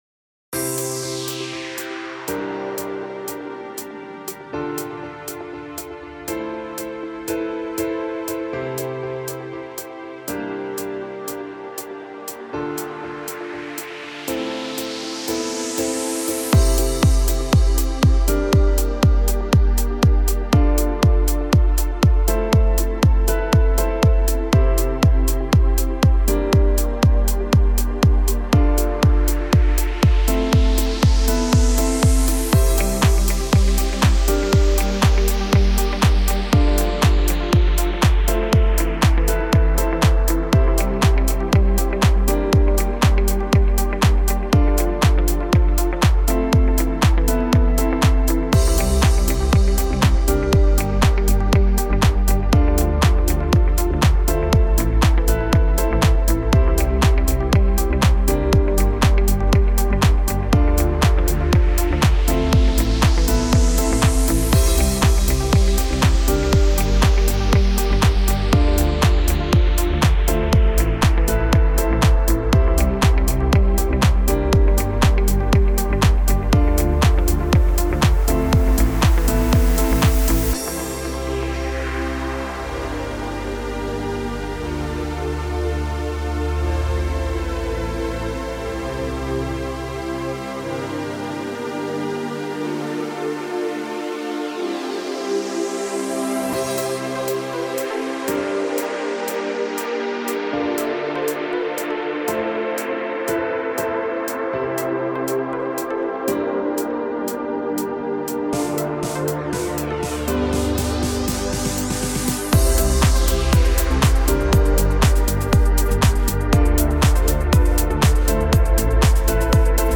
موسیقی بی کلام , پر‌انرژی , تدوین فیلم و عکس , رقص
موسیقی بی کلام پر انرژی موسیقی بی کلام دنس